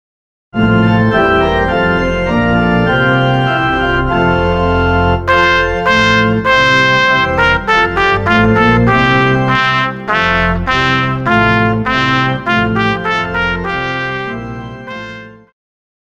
Classical
Band
Instrumental
Only backing